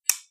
switch5.wav